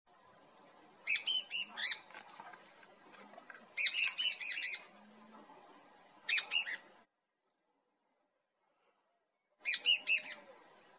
Greater Wagtail-Tyrant (Stigmatura budytoides)
Life Stage: Adult
Detailed location: Cercanias Laguna de Utracán
Condition: Wild
Certainty: Recorded vocal